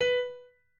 pianoadrib1_21.ogg